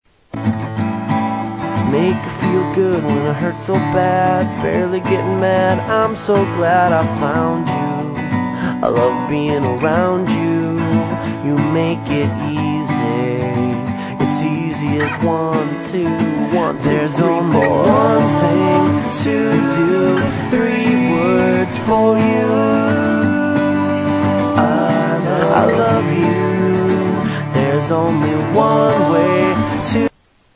• Rock Ringtones